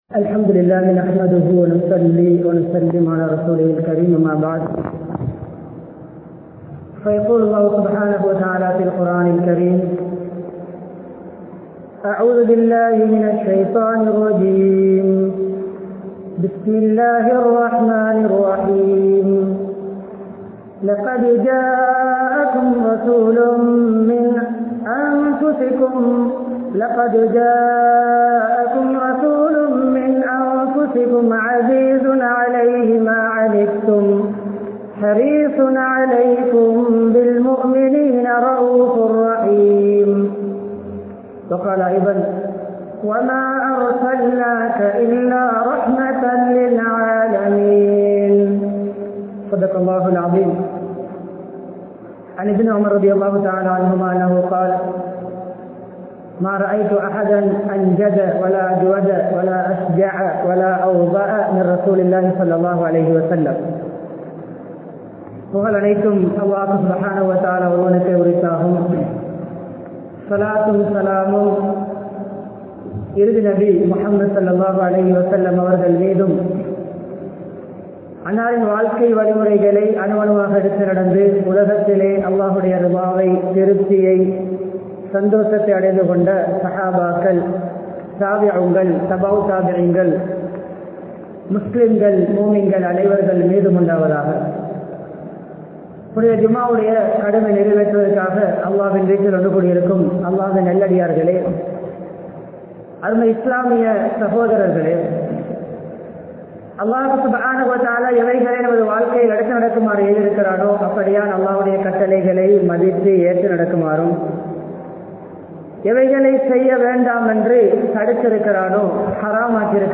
Nabi Avarhalai Unmaiyaaha Neasippoam (நபியவர்களை உண்மையாக நேசிப்போம்) | Audio Bayans | All Ceylon Muslim Youth Community | Addalaichenai
Panadura, Pallimulla Jumua Masjith